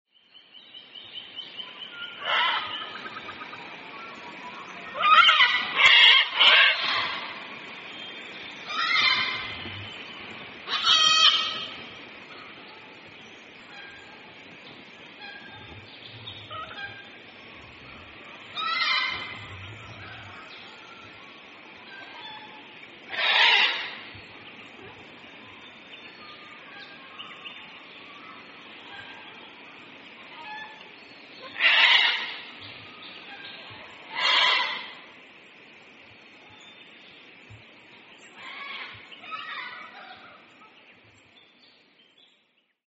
Sulphur-crested Cockatoo - Cacatua galerita
Voice: raucous screeching.
Call 1: Screeching.
Sulphur_cr_cocky.mp3